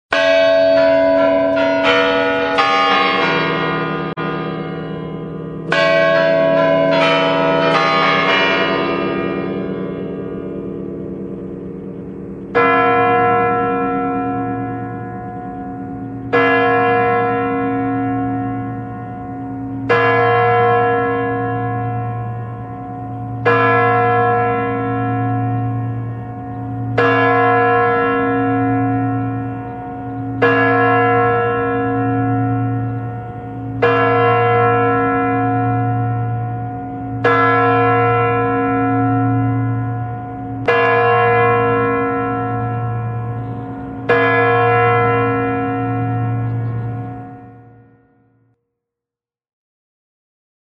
На этой странице собраны уникальные звуки Парада Победы: марши военных оркестров, рев моторов бронетехники, аплодисменты зрителей.
Парад Победы начинается со звука курантов на Красной площади